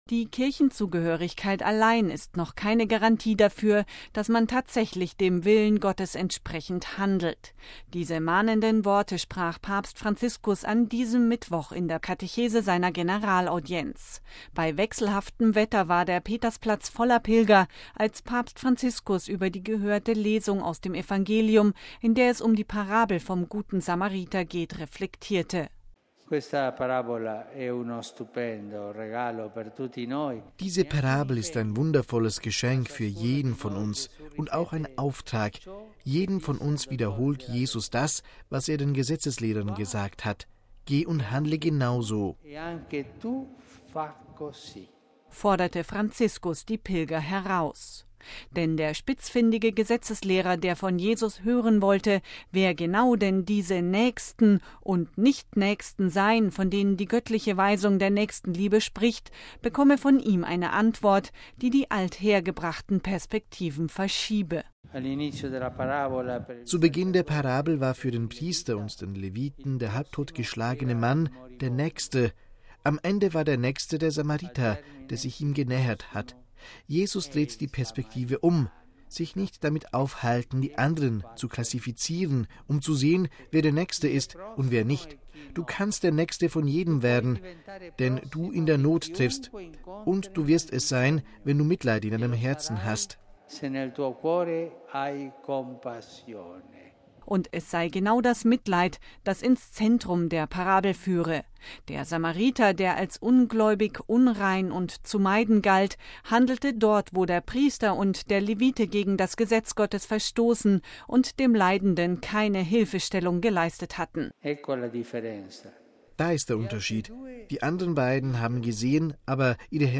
Die Kirchenzugehörigkeit allein ist noch keine Garantie dafür, dass man tatsächlich dem Willen Gottes entsprechend handelt. Diese mahnenden Worte sprach Papst Franziskus an diesem Mittwoch in der Katechese seiner Generalaudienz. Bei wechselhaftem Wetter war der Petersplatz voller Pilger, als Papst Franziskus über die gehörte Lesung aus dem Evangelium, in dem es um die Parabel vom Guten Samariter ging, reflektierte.